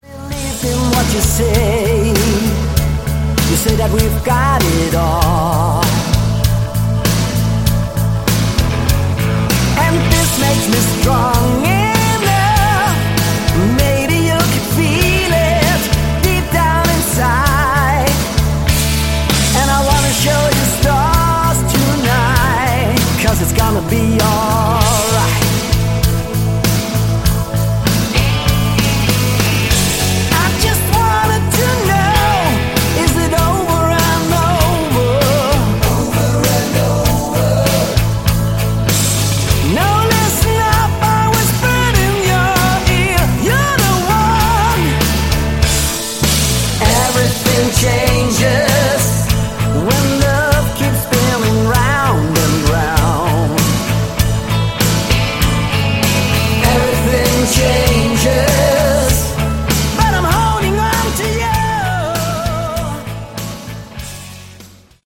Category: Melodic Rock
Vocals
Keyboards
Sax
Guitars
Drums
Bass